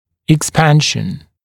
[ɪk’spænʃn] [ek-] [ик’спэншн] [эк-] расширение